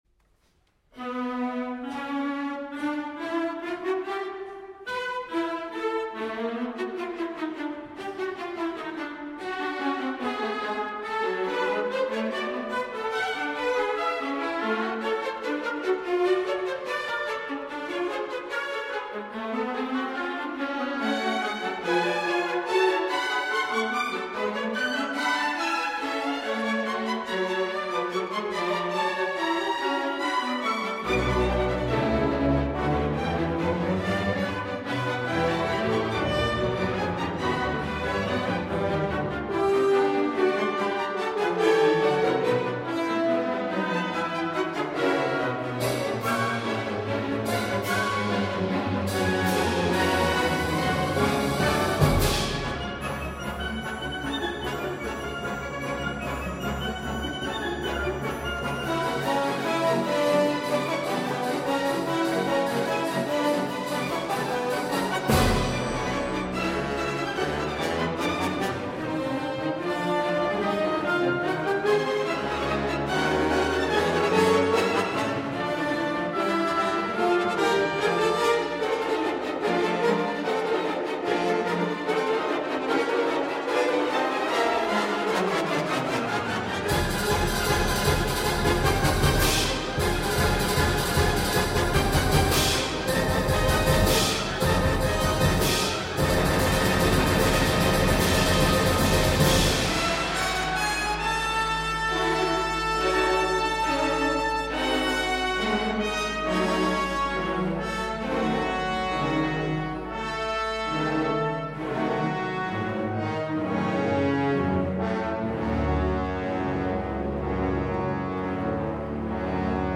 • Fragment 5: Het fugato uit het laatste deel.
5.a prachtige opbouw, waarbij in begin m.n. de motief-inzetten niet alleen goed te horen zijn, maar ook de ritmiek zo sterk werkt in de verschillende partijen. en ik hoor die strakke, felle bekkenslagen hier weer, die ik in de live-uitvoering van jaap van zweden zo mooi vond. maar ook door het te kunnen zien.